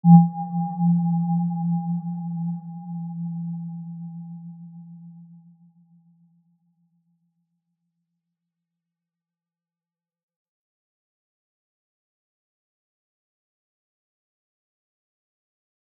Aurora-G3-mf.wav